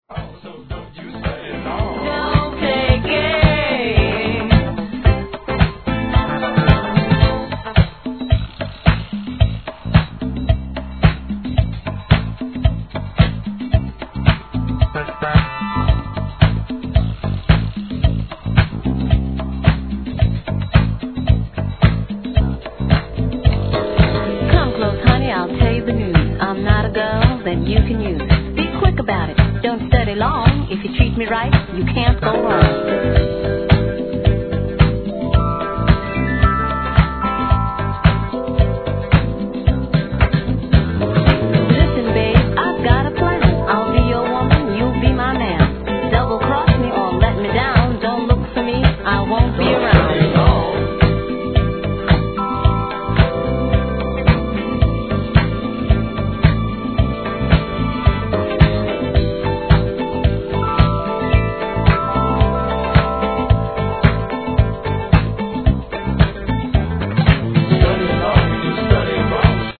SOUL/FUNK/etc...
中盤のRAP辺りからの展開が素晴らしい!!